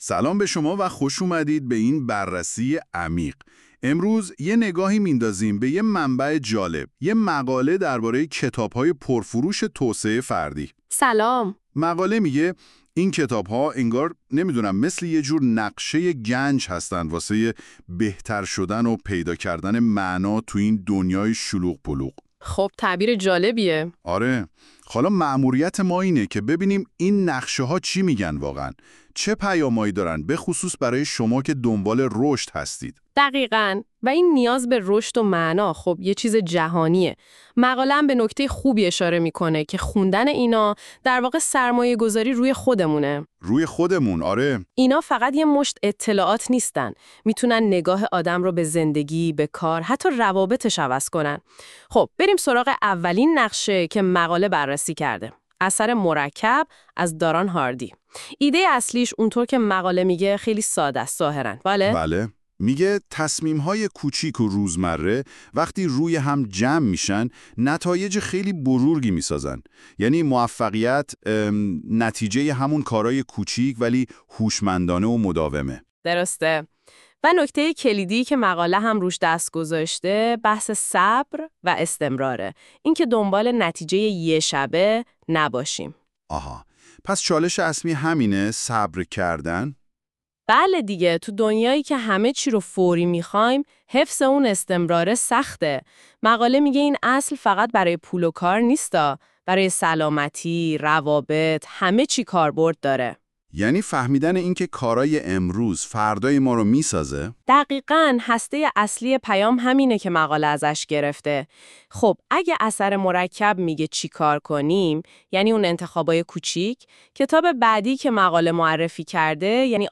🎧 خلاصه صوتی بهترین کتاب های توسعه فردی
این خلاصه صوتی به صورت پادکست و توسط هوش مصنوعی تولید شده است.